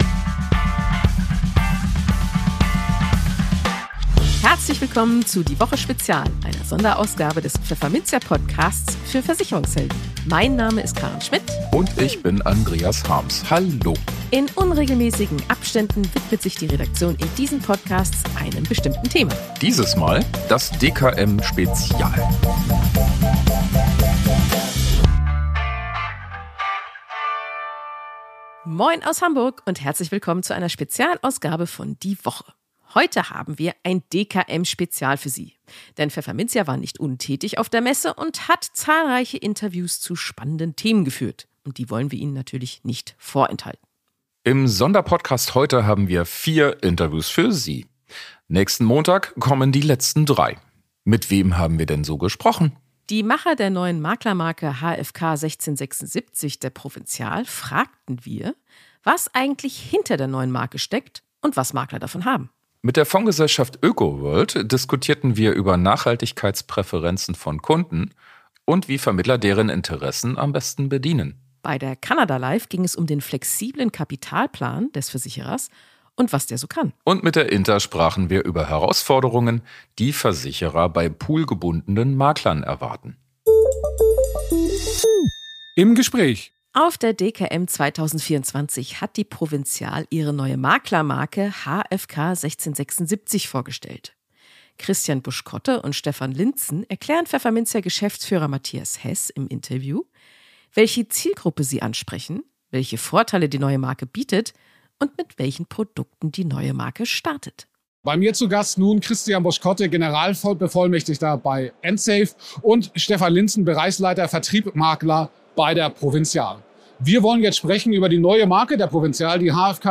Heute haben wir ein DKM-Spezial für Sie. Denn Pfefferminzia war nicht untätig und hat auf der Messe zahlreiche Interviews zu spannenden Themen geführt.